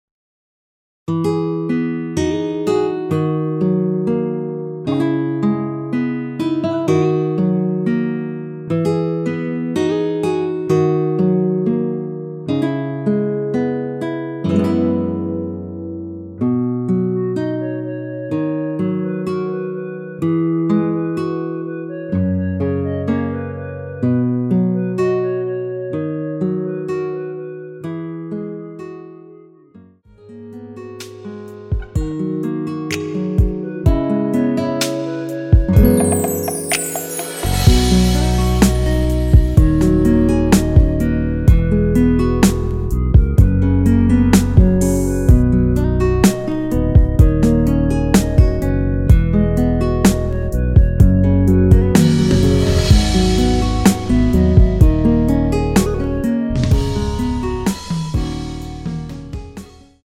원키에서(-1)내린 멜로디 포함된 MR입니다.
노래방에서 노래를 부르실때 노래 부분에 가이드 멜로디가 따라 나와서
앞부분30초, 뒷부분30초씩 편집해서 올려 드리고 있습니다.